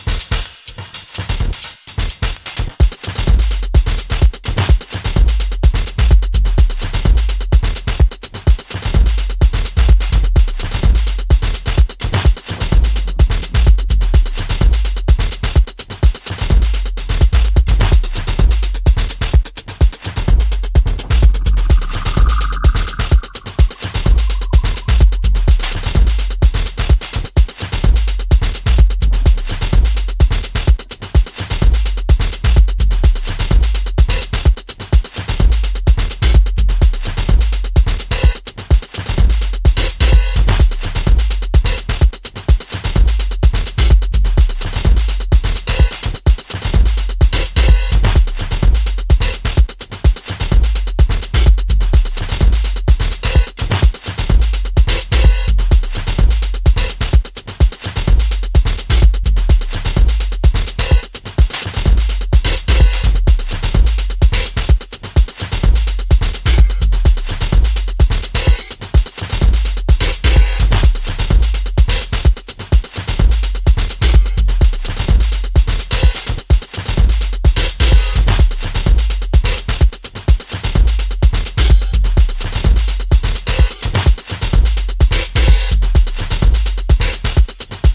supplier of essential dance music
a more stripped back, lo fi, floor focussed sound